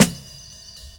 Boom-Bap Snare 80.wav